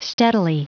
Prononciation du mot steadily en anglais (fichier audio)
Prononciation du mot : steadily